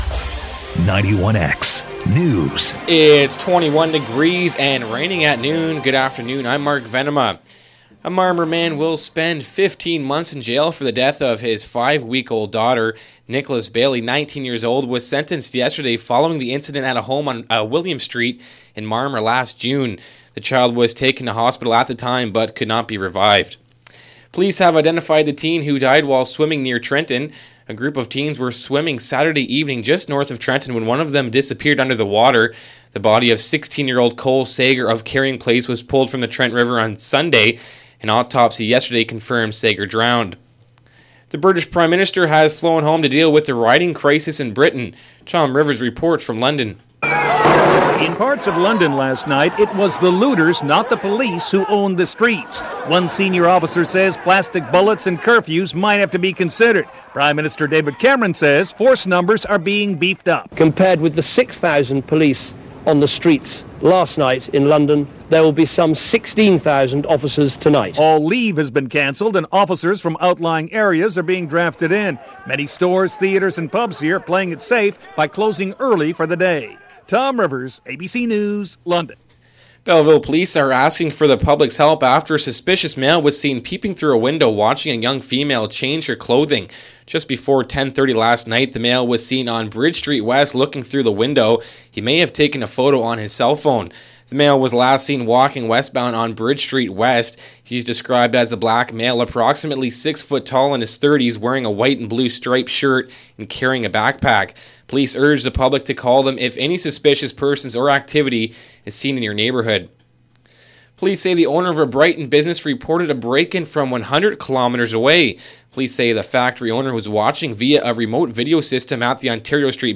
91x News, August 9, 2011, 12pm